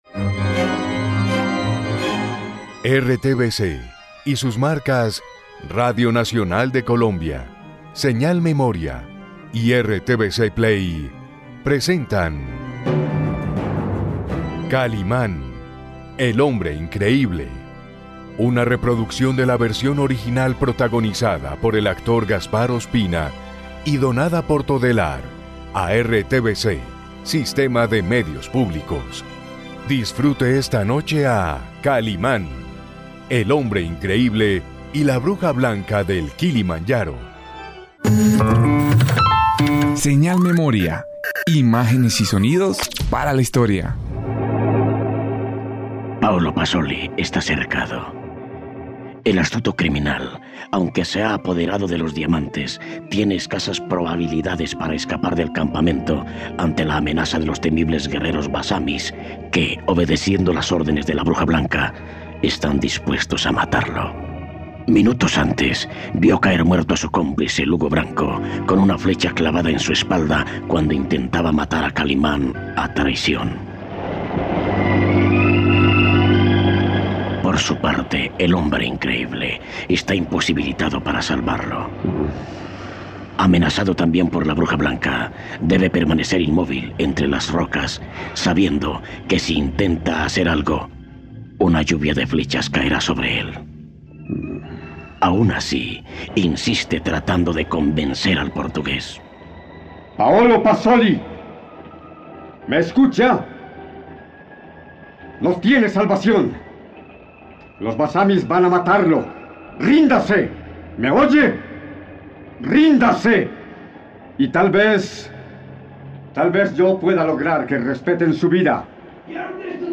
Novela colombiana